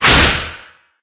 sounds / mob / wither / shoot.ogg
shoot.ogg